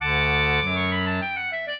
clarinet
minuet0-7.wav